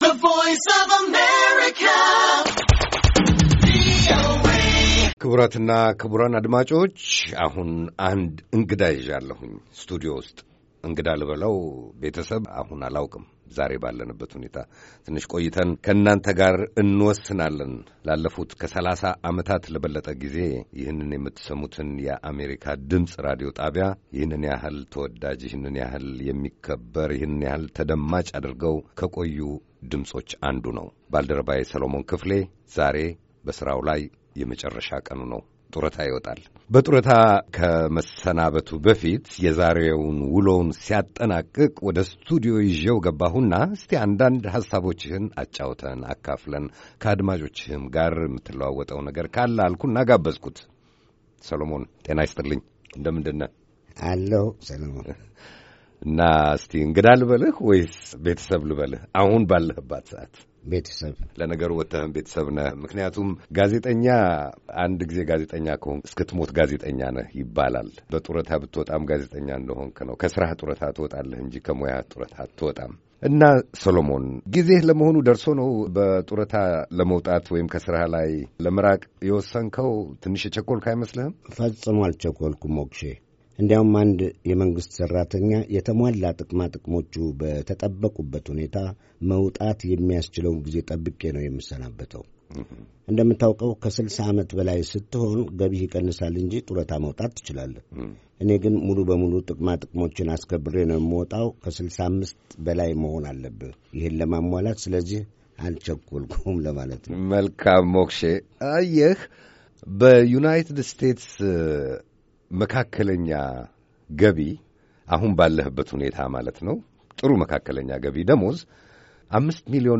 አጭር የመሰናበቻ ቃለ-ምልልስ